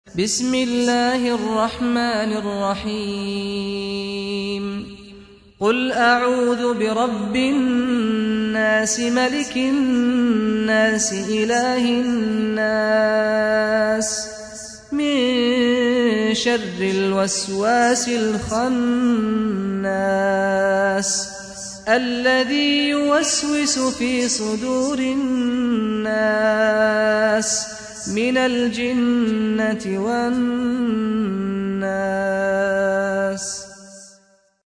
سورة الناس | القارئ سعد الغامدي